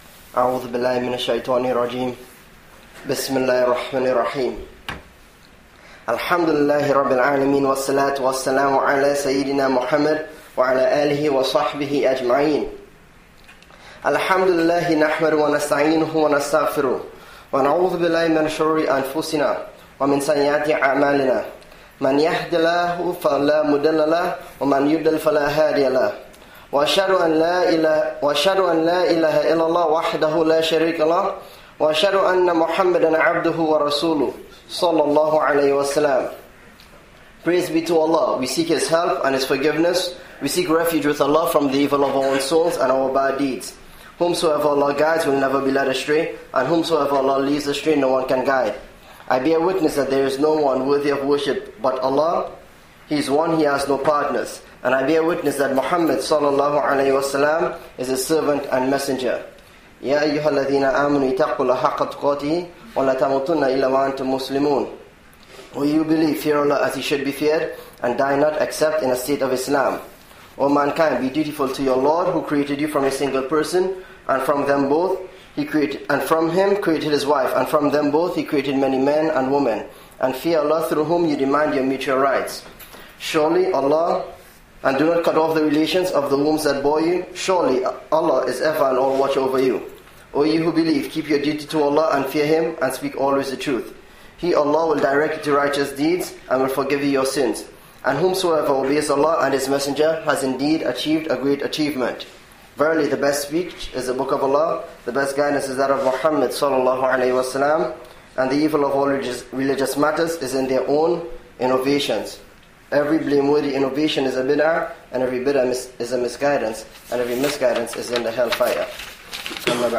(If above player does not work, click " Attachment: The Two Highways " for audio recording of the khutbah) - [opens in a separate window] ...